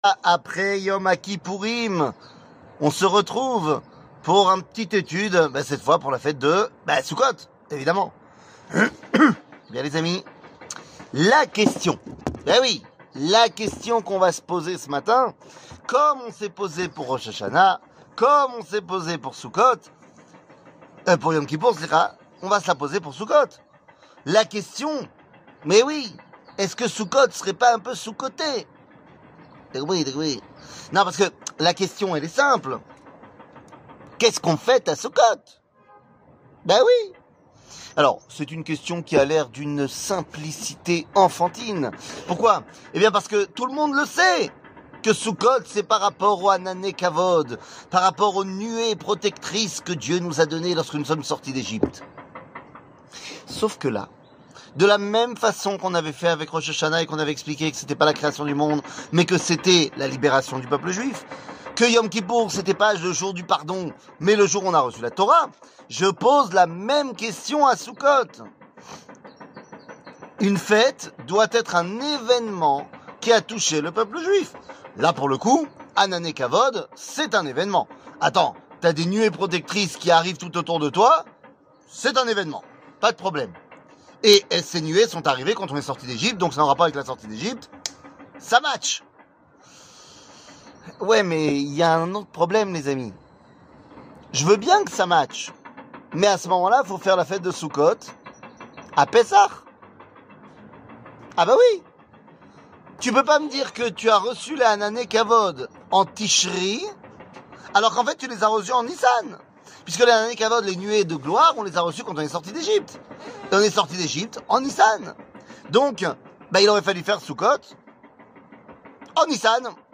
שיעור מ 27 ספטמבר 2023 07MIN הורדה בקובץ אודיו MP3
שיעורים קצרים